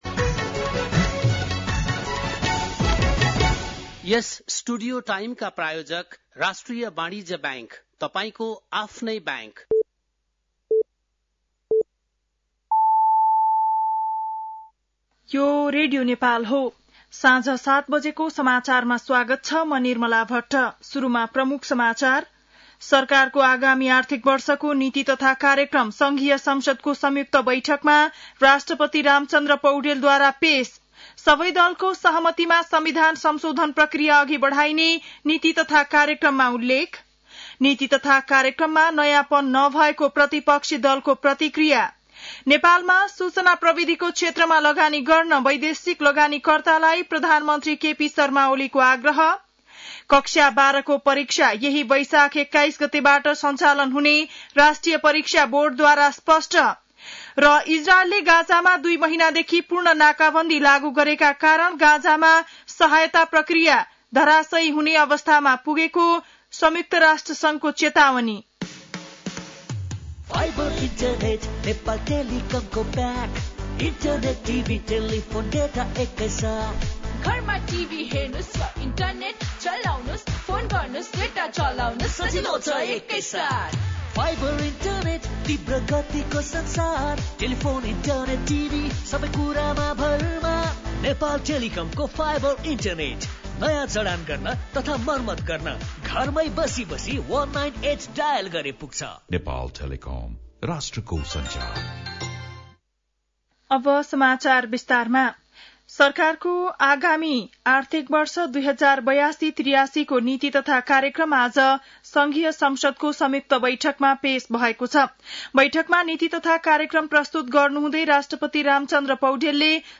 बेलुकी ७ बजेको नेपाली समाचार : १९ वैशाख , २०८२